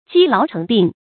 積勞成病 注音： ㄐㄧ ㄌㄠˊ ㄔㄥˊ ㄅㄧㄥˋ 讀音讀法： 意思解釋： 因長期工作，勞累過度而生了病。